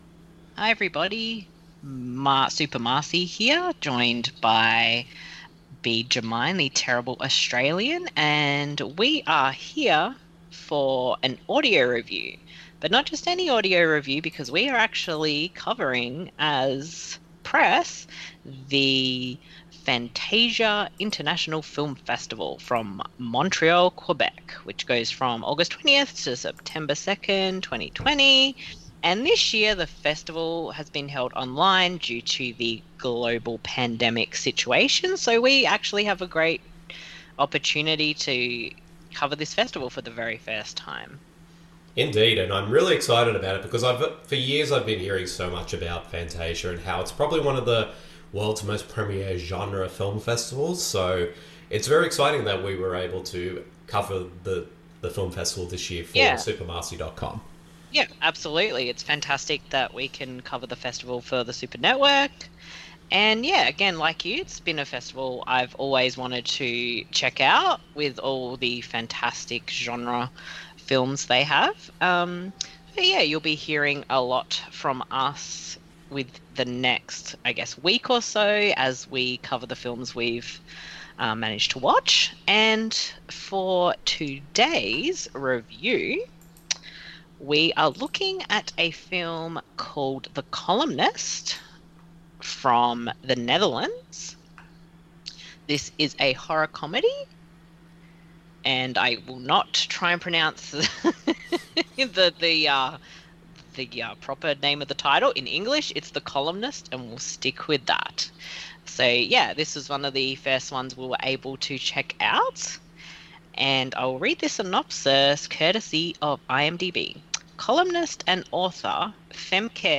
[Fantasia 2020] Audio Review: The Columnist (2019)
The following review of the film is in an audio format.